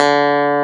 CLAV G2.wav